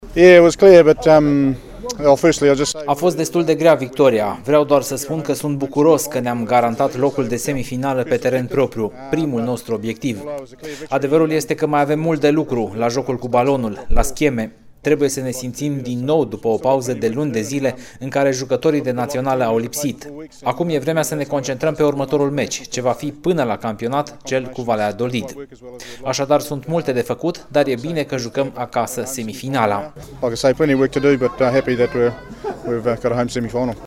Declaraţii la final de meci: